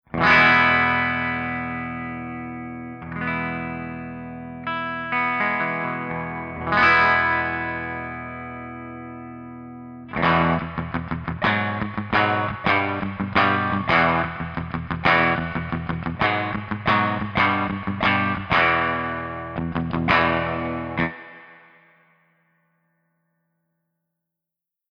055_FENDERTWIN_STANDARDREVERB_P90.mp3